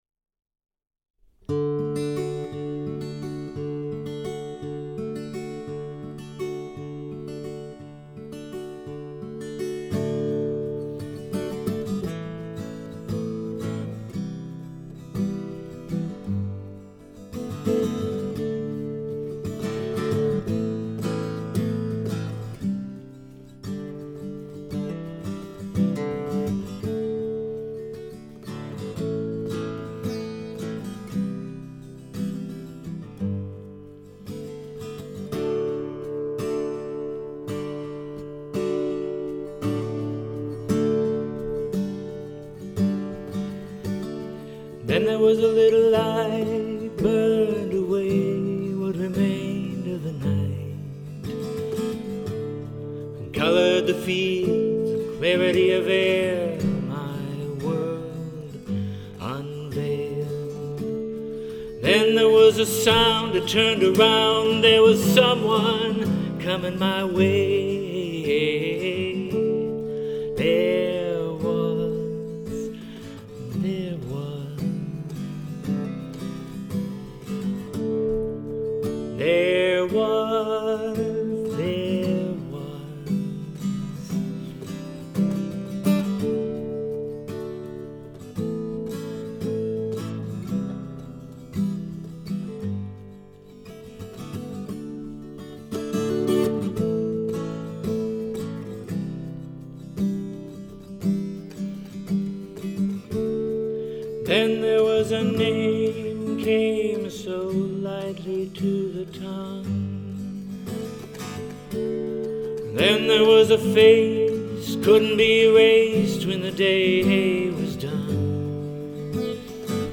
I recorded this on my Mac to send her a demo… so I share it here: